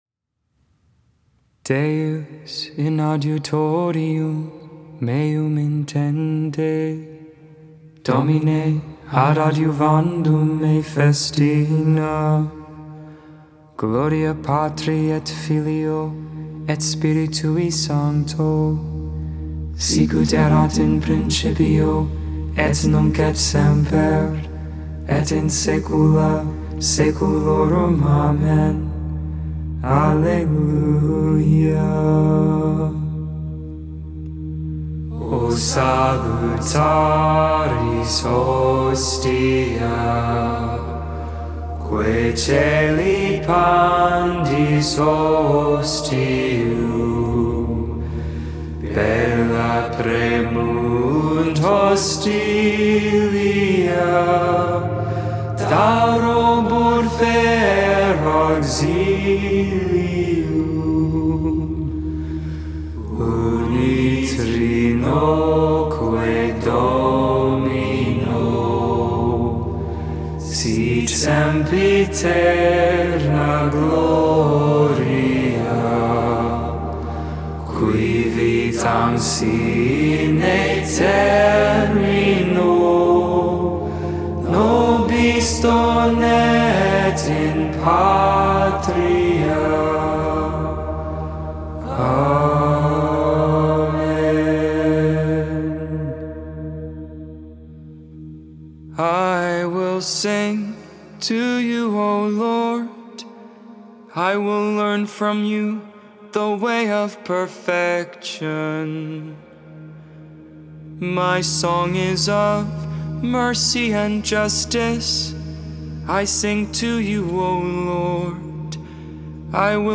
8.16.22 Lauds, Tuesday Morning Prayer
The Liturgy of the Hours, Lauds, Morning Prayer for 20th Tuesday in Ordinary Time, August 16th, 2022.